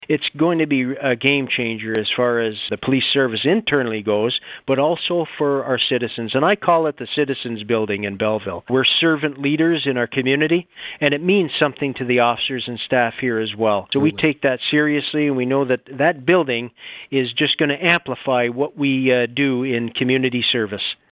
Police Chief Ron Gignac tells Quinte News it’s going to make a massive impact on policing in the city.